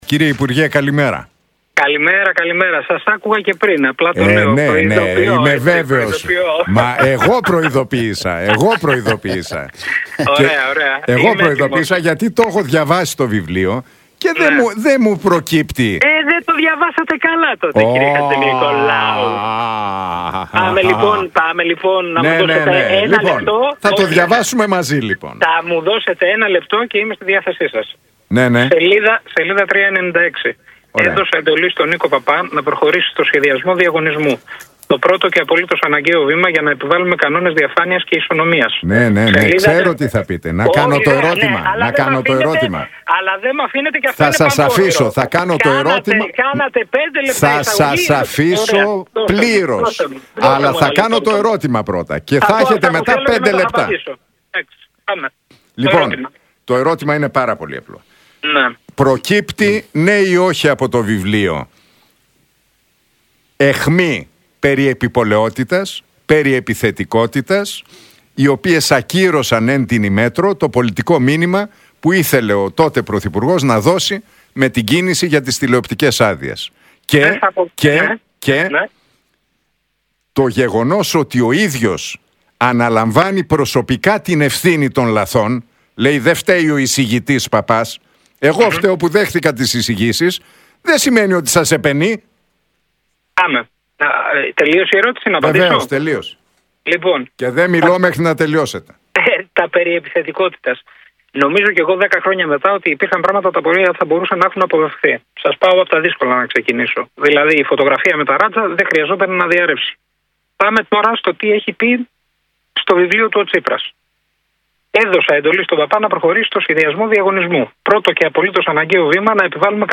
Ο Νίκος Παππάς ερωτήθηκε για τη δήλωση του ότι το βιβλίο «με δικαιώνει 100%» και διάβασε τα συγκεκριμένα αποσπάσματα που θεωρεί, ότι, κατά τη γνώμη του τον δικαιώνουν για τον διαγωνισμό για τις τηλεοπτικές άδειες και απάντησε: